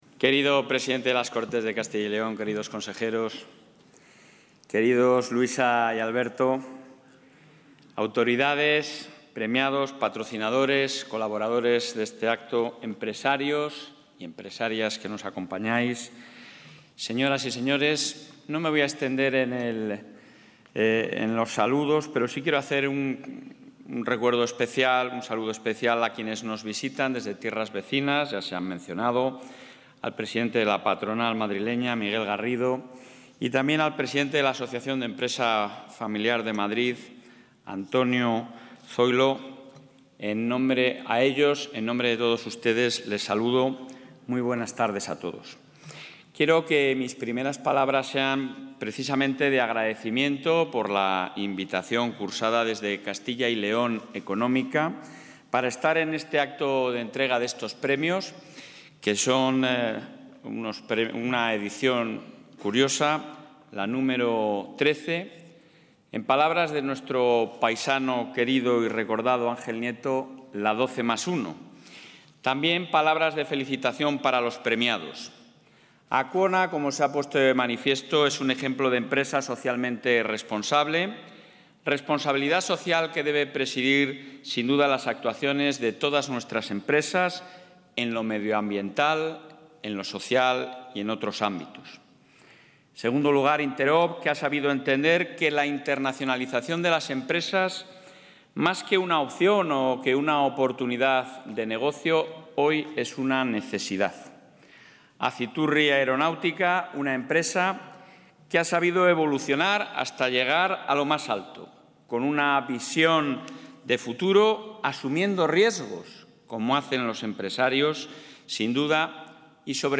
Audio presidente.